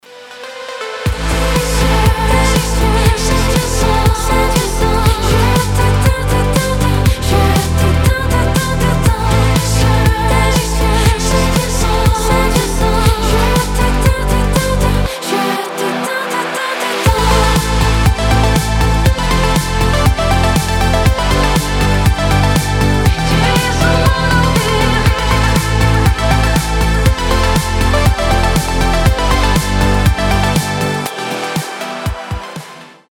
танцевальные
synth pop